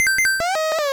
tetris_line_clear.wav